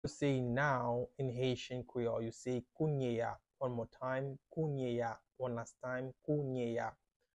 “Now” in Haitian Creole - “Kounye a” pronunciation by a native Haitian teacher
“Kounye a” Pronunciation in Haitian Creole by a native Haitian can be heard in the audio here or in the video below: